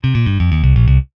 [jingle][1]
Mayo_Jingle.mp3